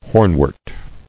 [horn·wort]